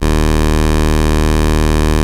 OSCAR 13 D#2.wav